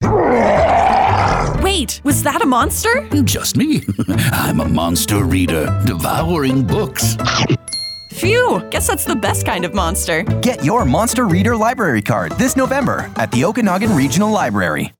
We are also sharing three brand new radio ads from our Monster Reader campaign.
In this spot, a friendly monster makes an unexpected entrance.
Okanagan-Regional-Library-Monster-Voice-Reveal-Nov2025.mp3